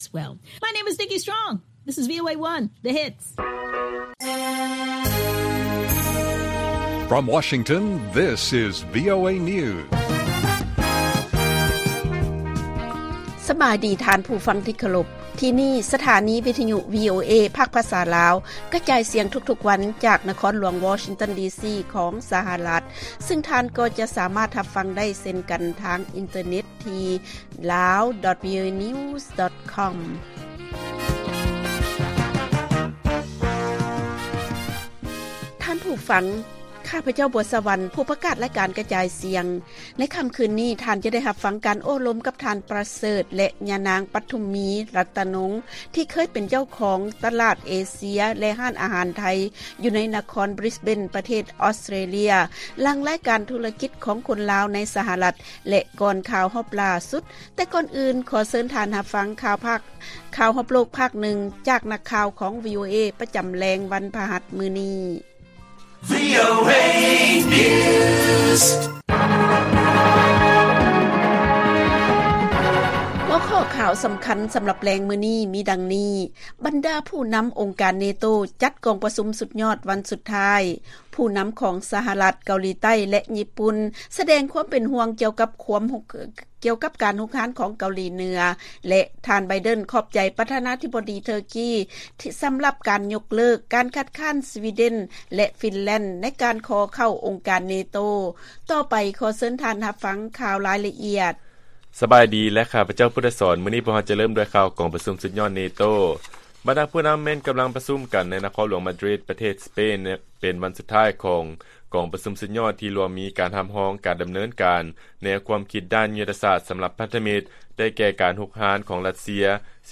ລາຍການກະຈາຍສຽງຂອງວີໂອເອ ລາວ: ບັນດາຜູ້ນຳອົງການ NATO ຈັດກອງປະຊຸມສຸດຍອດ ວັນສຸດທ້າຍ